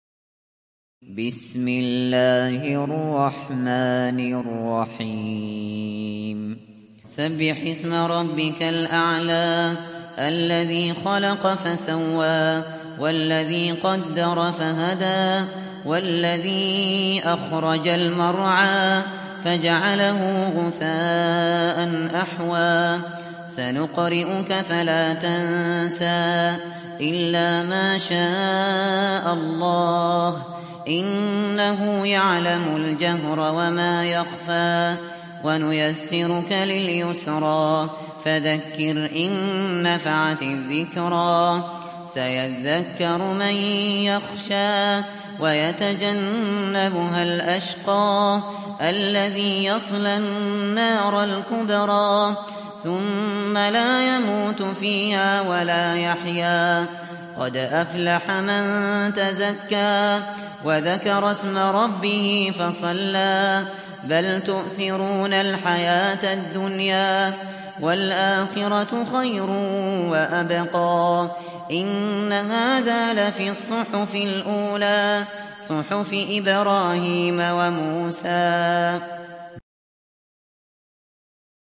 دانلود سوره الأعلى mp3 أبو بكر الشاطري (روایت حفص)